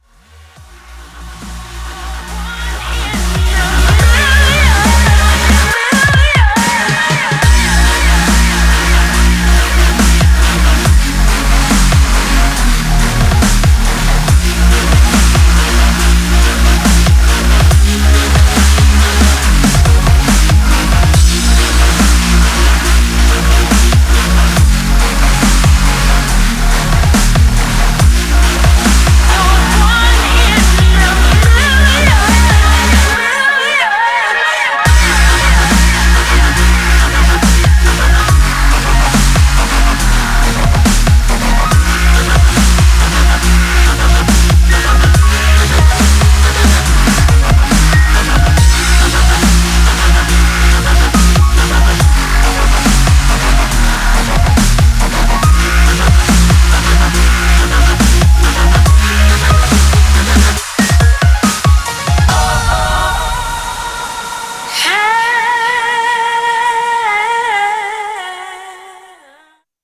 Styl: Dub/Dubstep, Drum'n'bass